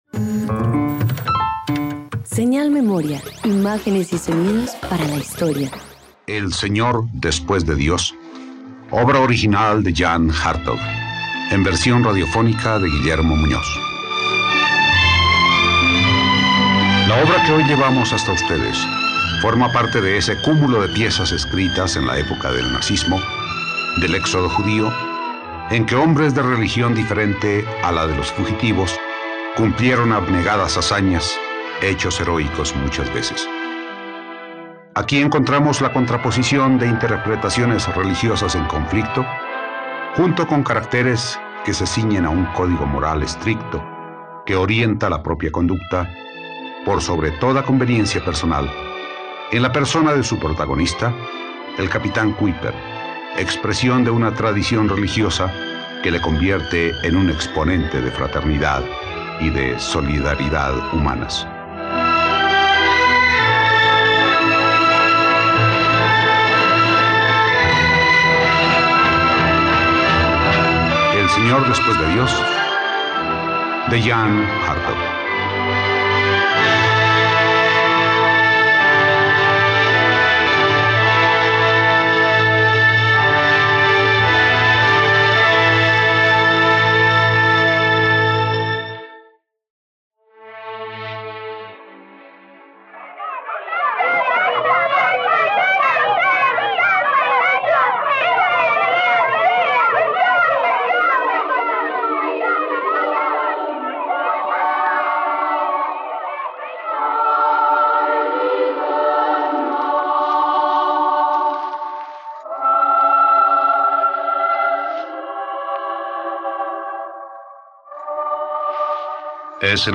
..Radioteatro. La obra de Jean de Hartog expone las consecuencias del antisemitismo durante la época nazi de los años 30.